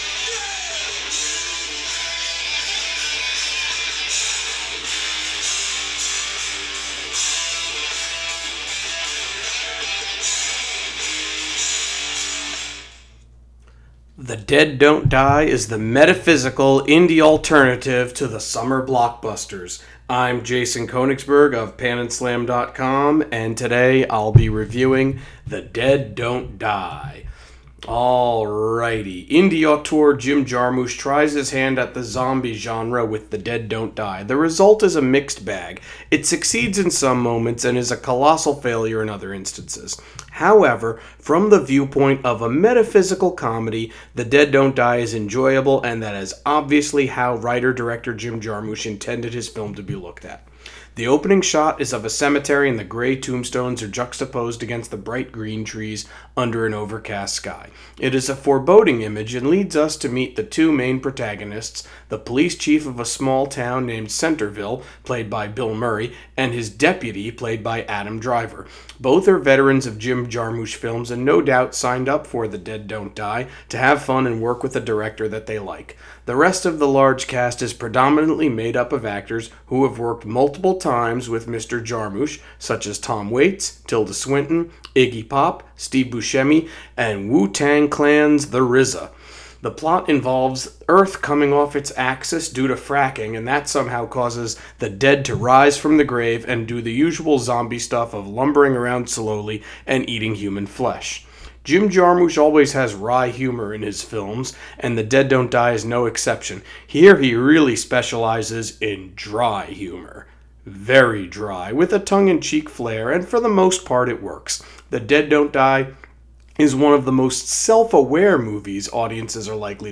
Movie Review: The Dead Don’t Die